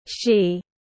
Cô ấy tiếng anh gọi là she, phiên âm tiếng anh đọc là /ʃiː/.
She /ʃiː/